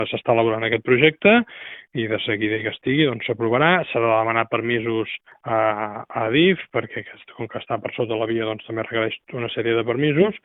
L’alcalde Buch ha recordat que s’haurà de demanar l’autorització a la gestora de la infraestructura ferroviària abans que es pugui començar a executar l’obra.